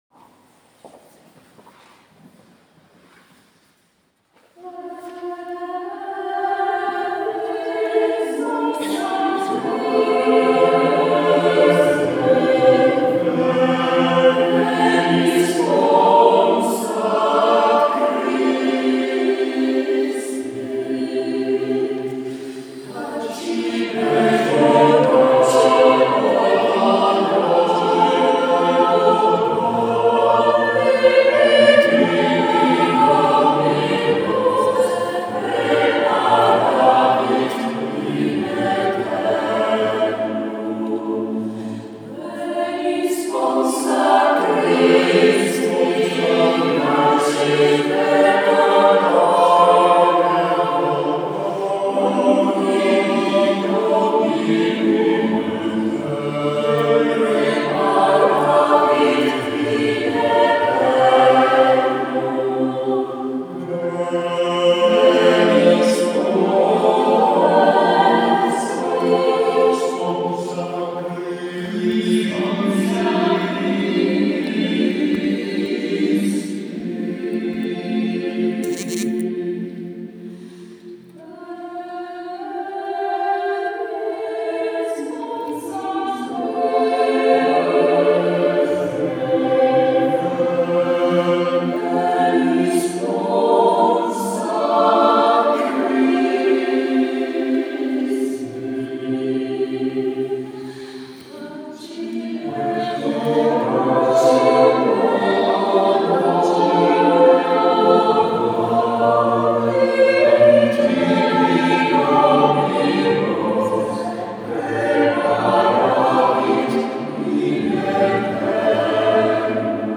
Concerto del 19 novembre 2022 – Chiesa di San Bartolomeo a Treviso